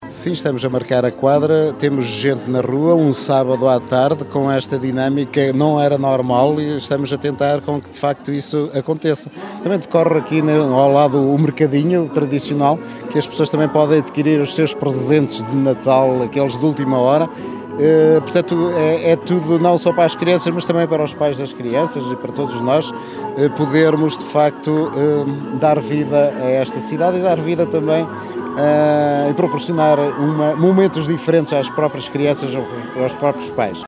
A Cidade Natal fica assim completa, com a chegada do Pai Natal à Quinta Natal, com uma dinâmica diferente em Macedo de Cavaleiros este ano, explica Duarte Moreno, presidente do município.